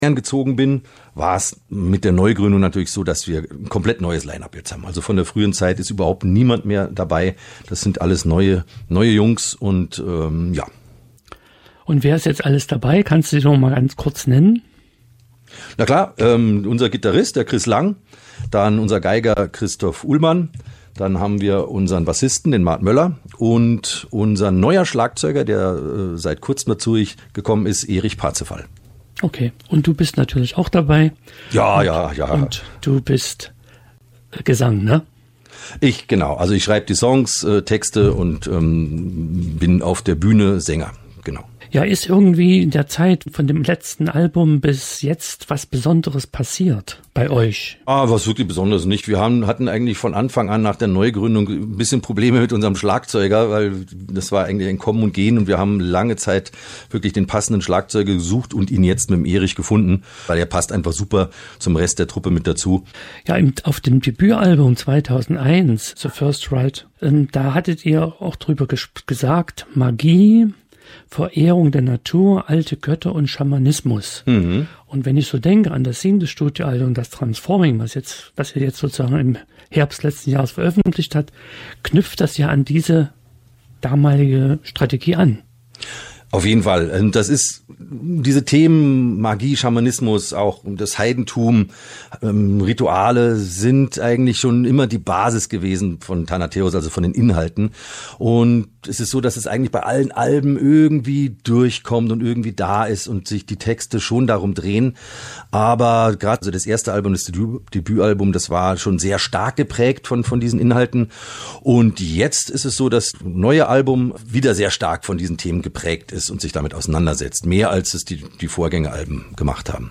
Musik von Rock bis Metal!